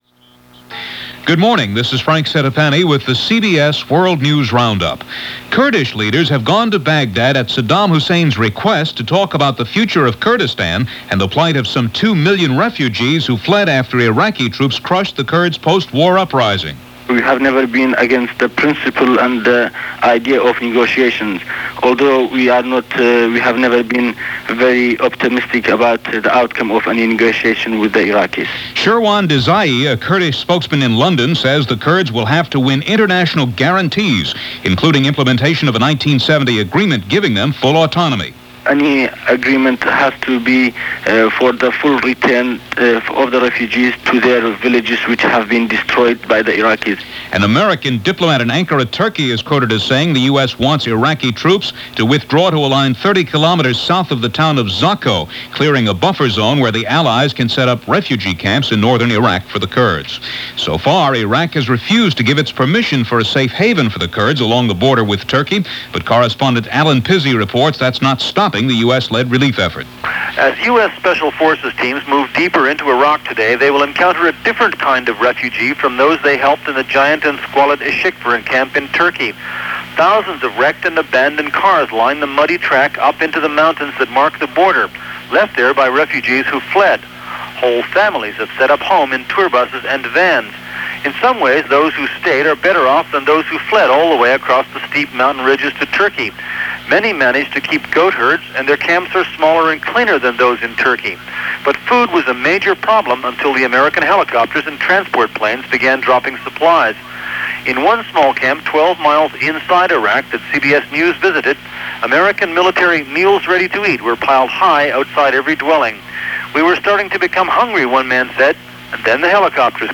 All that, and much more for this April 20, 1991 as reported by The CBS World News Roundup.